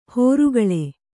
♪ hōrugaḷe